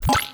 UIBeep_Message Pop Up.wav